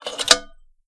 control_lock.wav